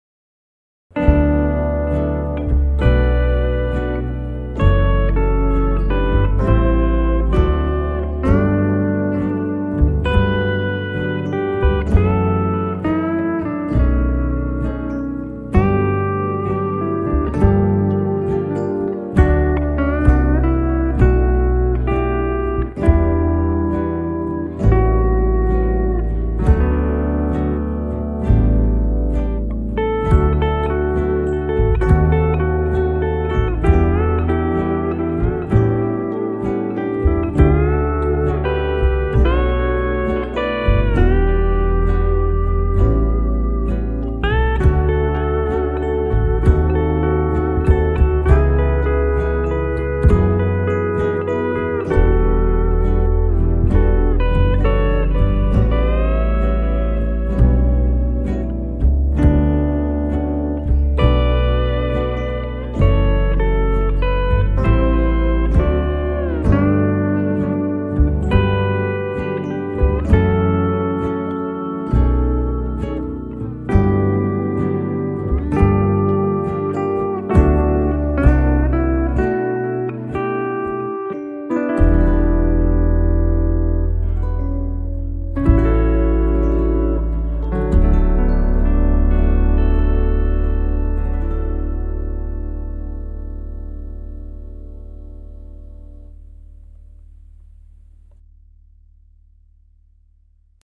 Great Chords.